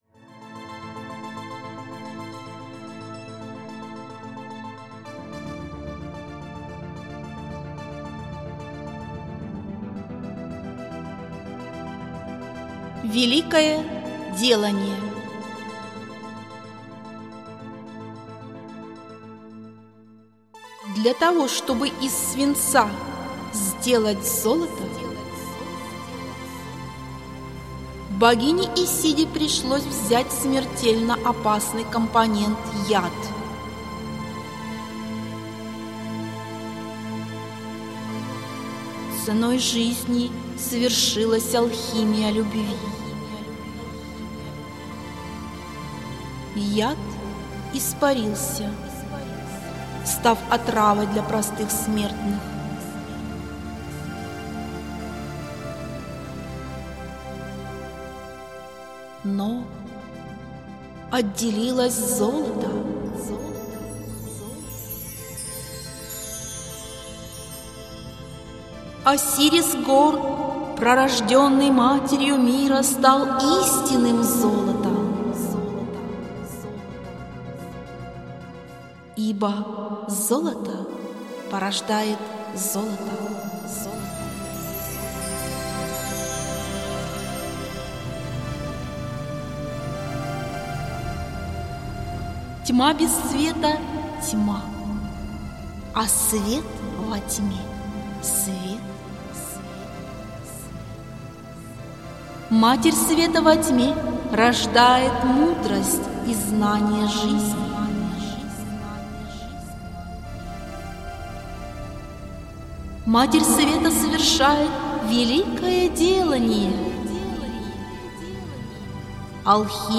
Читает Автор